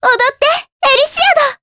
Ophelia-Vox_Skill5_jp.wav